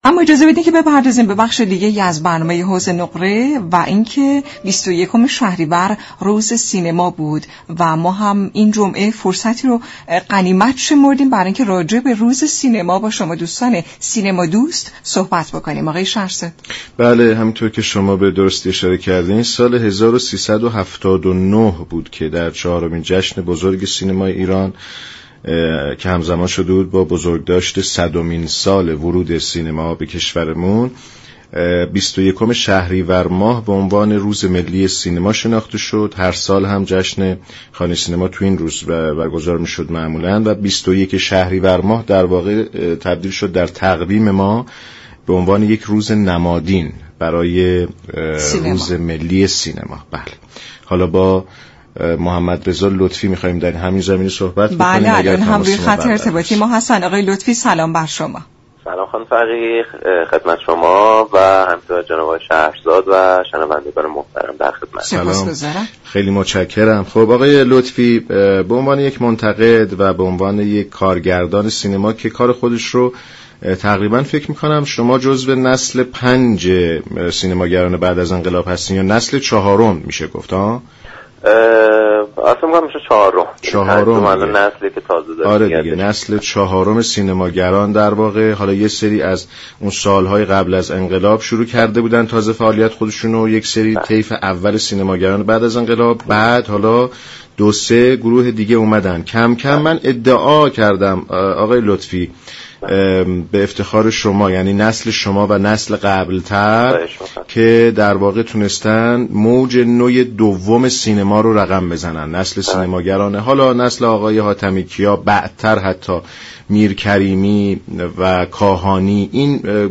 در گفت و گو با برنامه «حوض نقره»